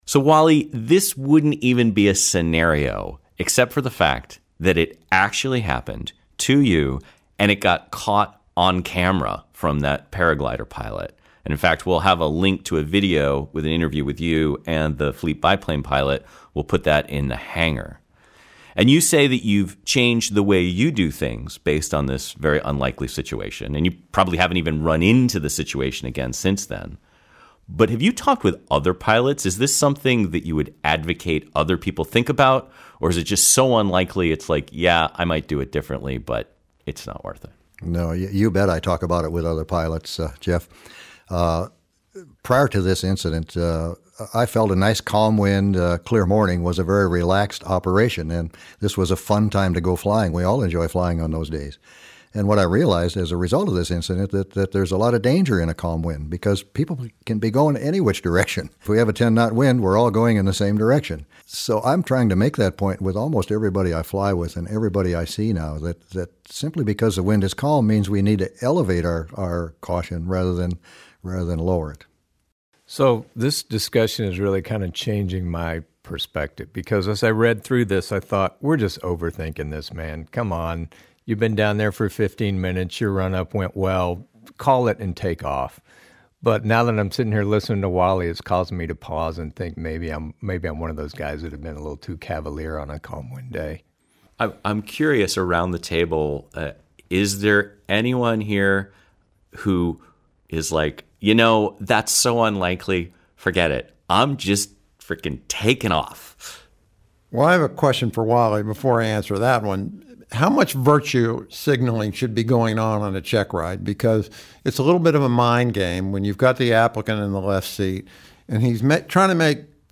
is_there_anybody_out_there_roundtable.mp3